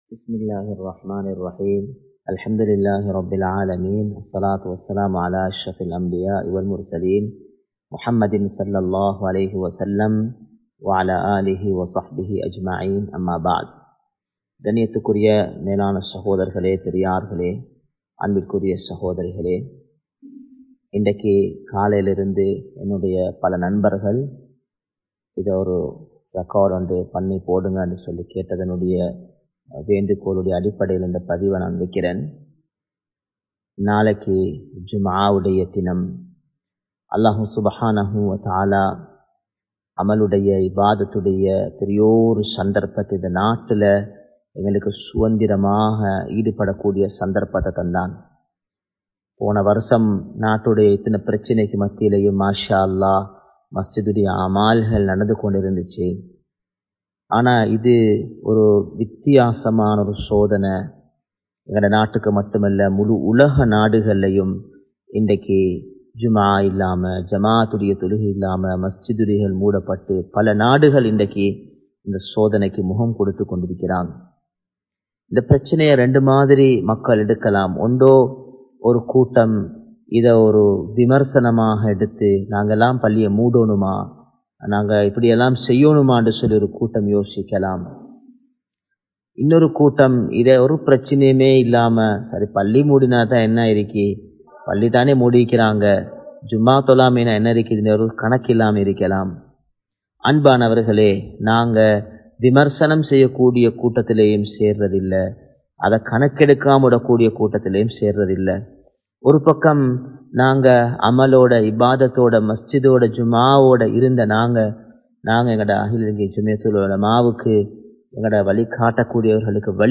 Bayans